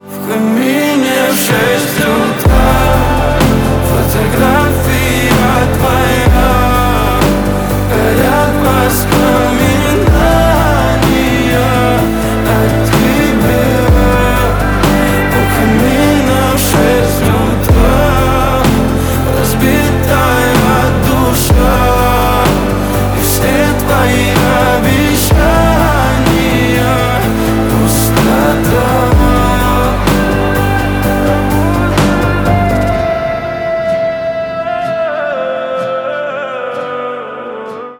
• Качество: 128, Stereo
гитара
лирика
грустные
красивый мужской голос
мелодичные
дуэт
пианино